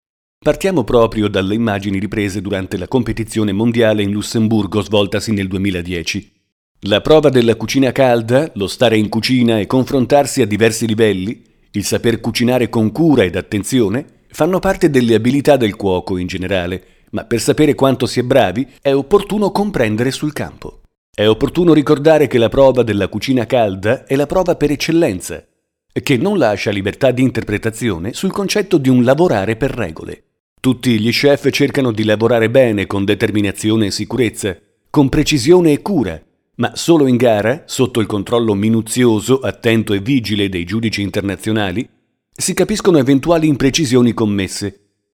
Kein Dialekt
Sprechprobe: Industrie (Muttersprache):
Speaker radio-tv-multimedia Soundesign - editing audio